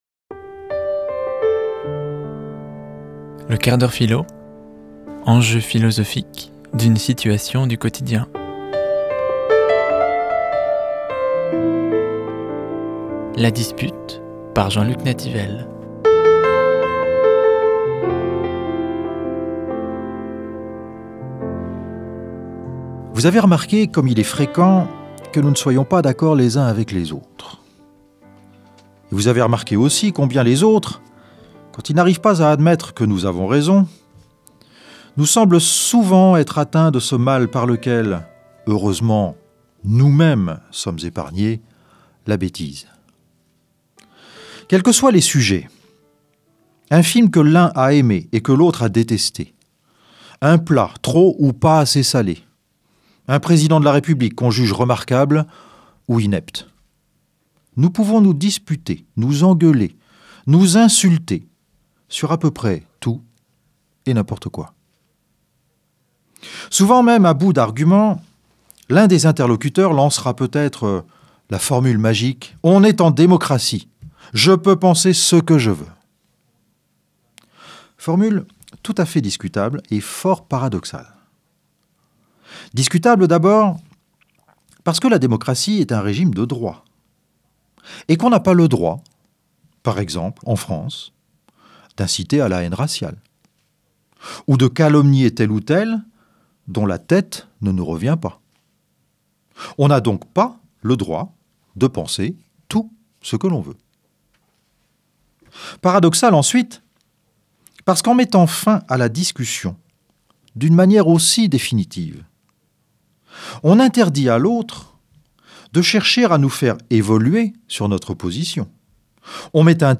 » Descartes, Discours de la méthode La chronique philosophie proposée par l’association Philosophia .